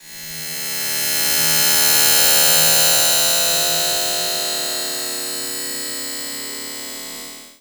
MAGIC_SPELL_Digital_01_mono.wav